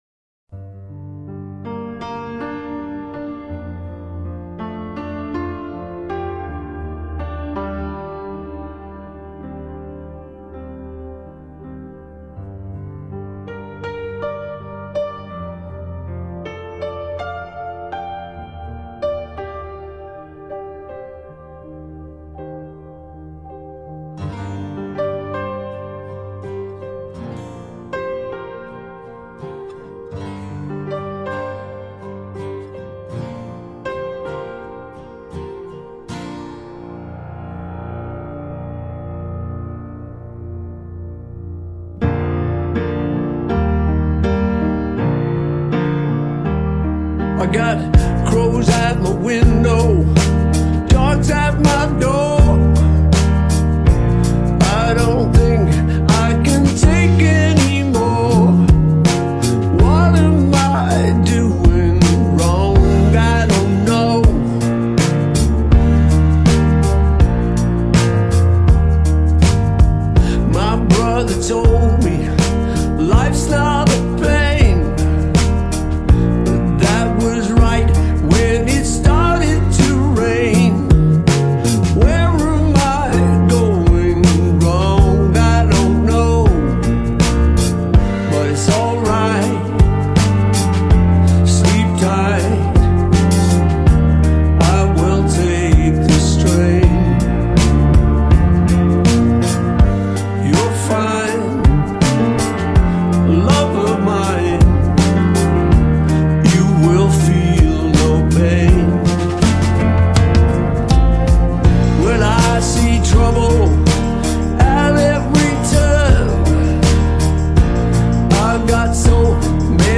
рок-музыка